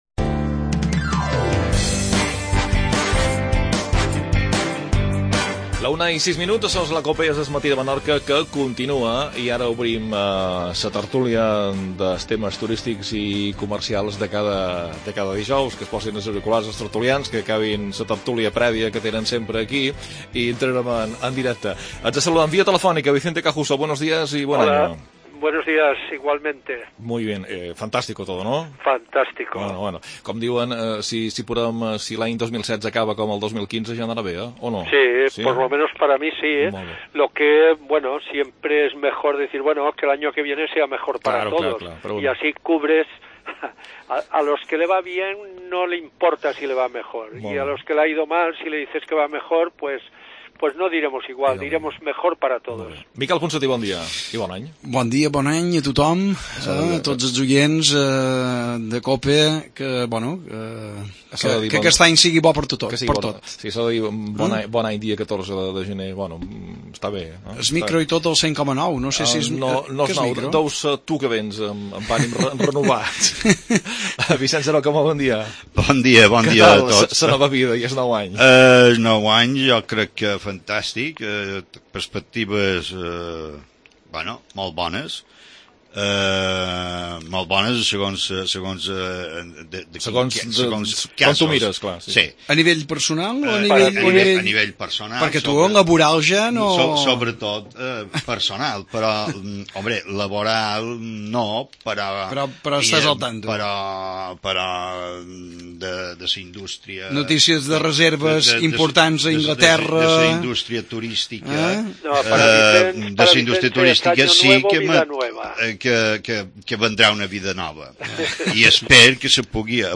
Tertulia.